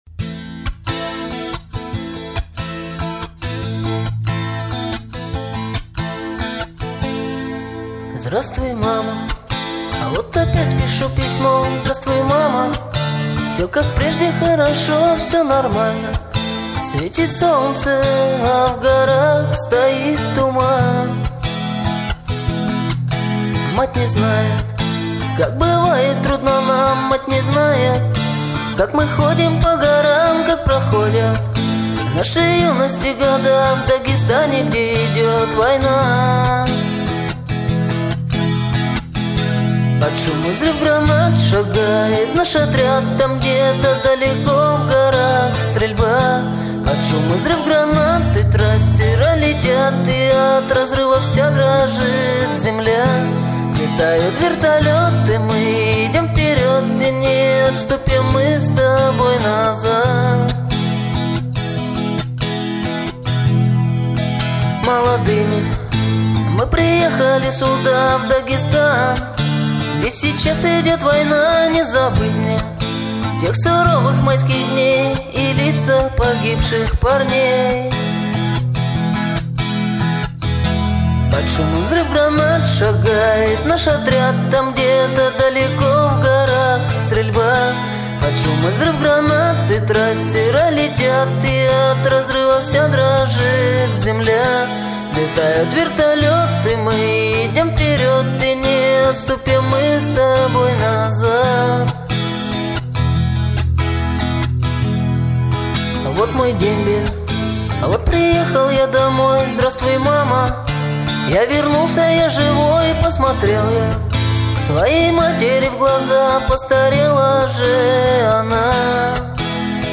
Категория: Песни под гитару